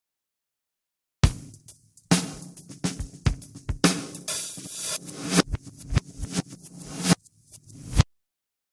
drumloop
60540-drumloop.mp3